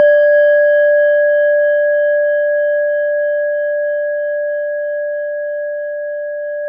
Index of /90_sSampleCDs/E-MU Formula 4000 Series Vol. 4 – Earth Tones/Default Folder/Japanese Bowls